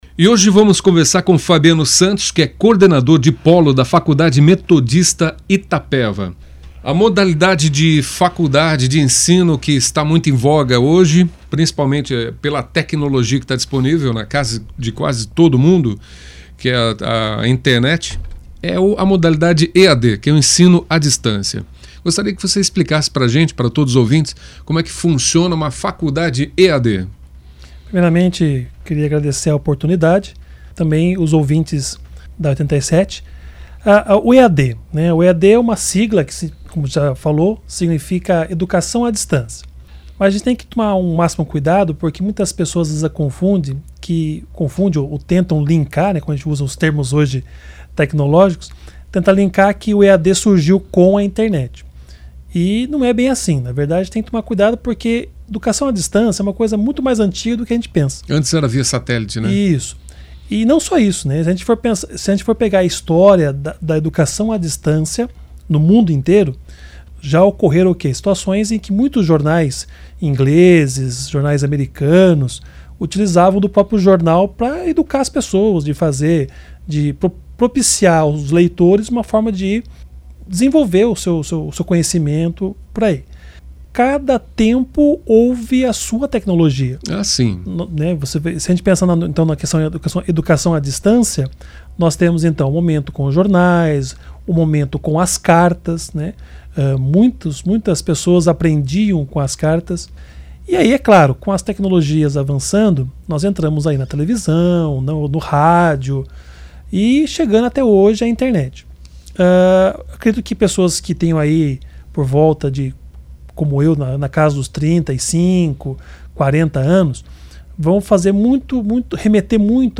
Ouça a entrevista na íntegra .